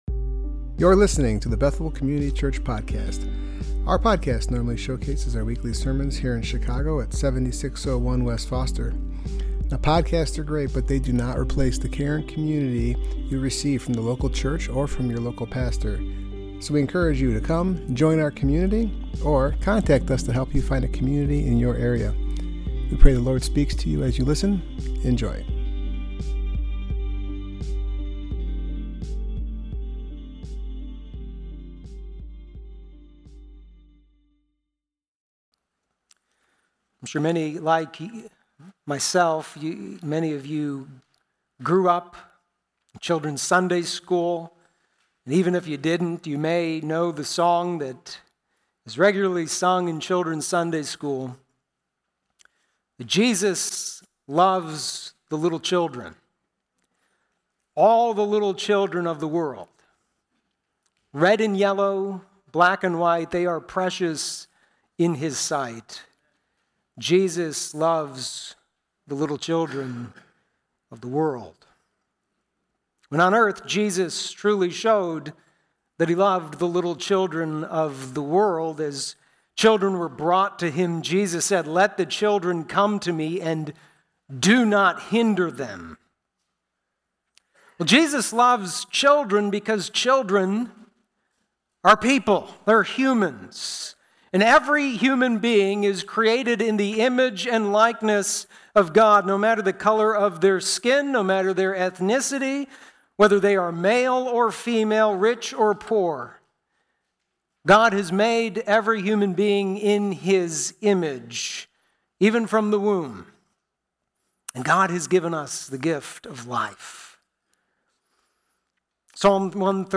Service Type: Worship Gathering Topics: abortion , sanctity of Life